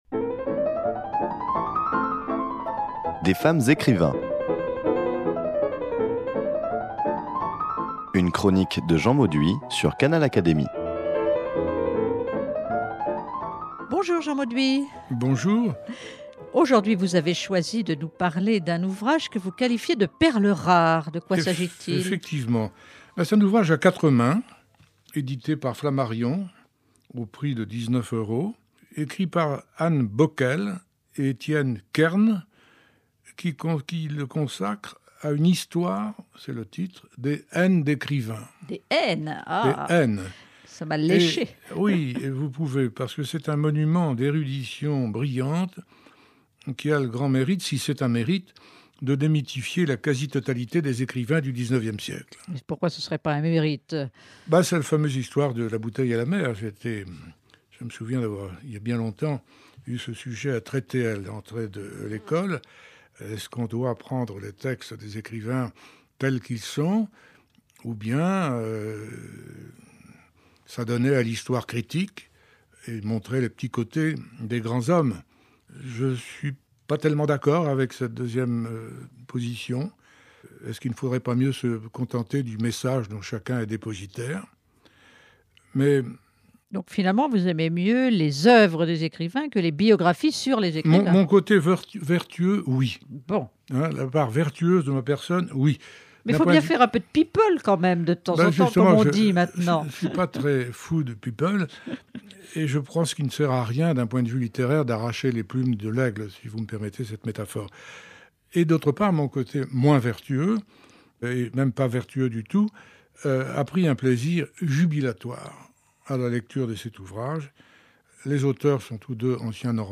Cette histoire des haines d’écrivains -parmi lesquels des académiciens !- dûe à Anne Boquel et Etienne Kern, est ici présentée par notre chroniqueur littéraire